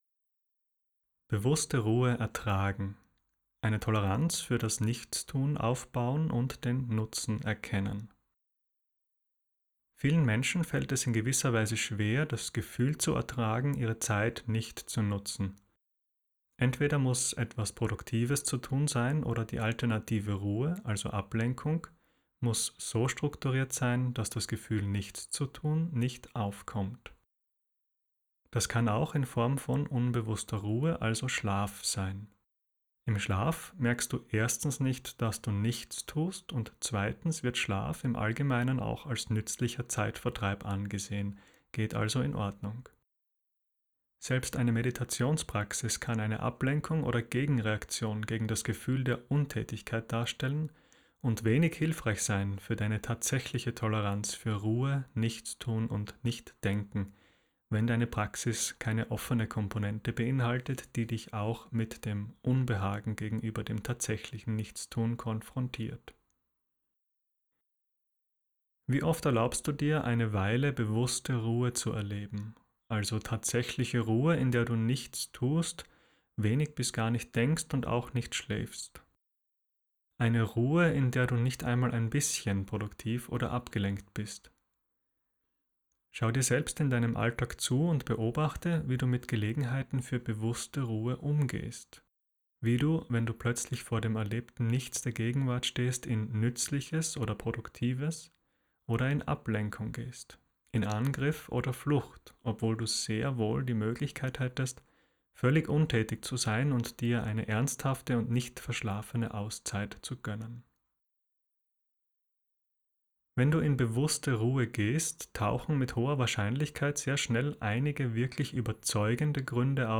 Text zum Anhören (7 Min.):